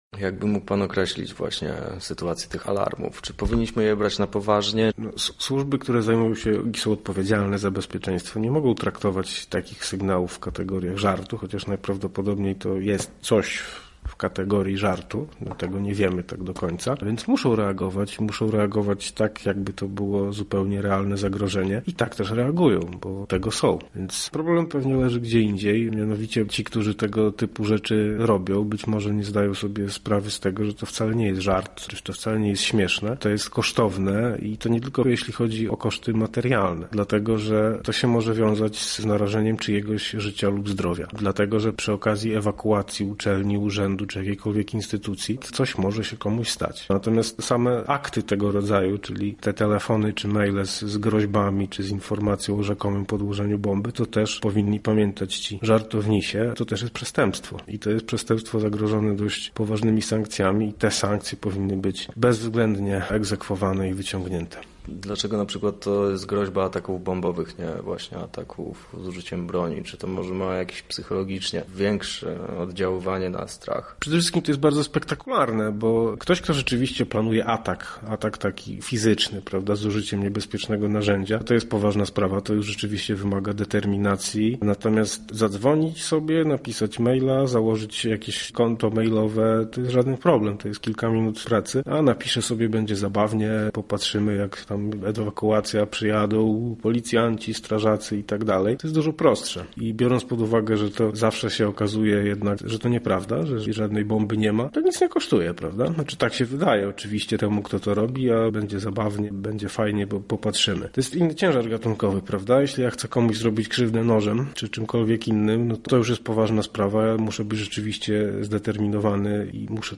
Nasz reporter